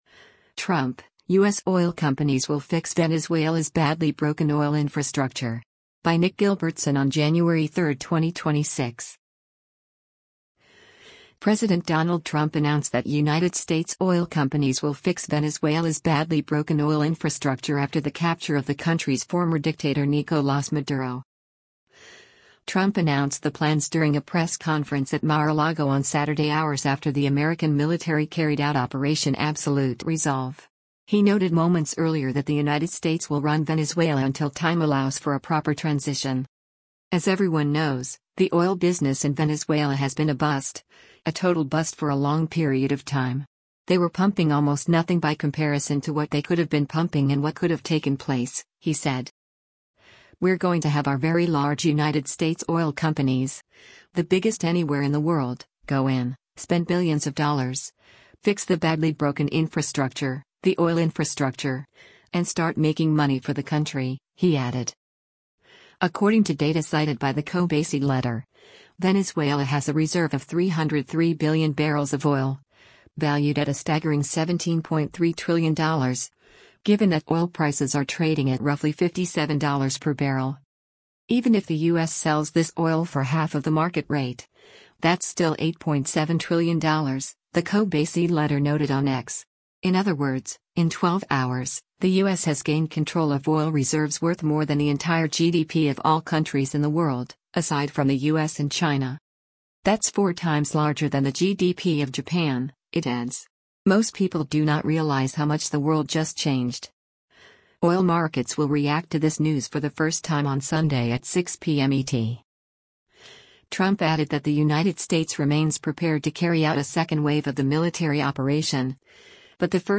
Trump announced the plans during a press conference at Mar-a-Lago on Saturday hours after the American military carried out operation Absolute Resolve.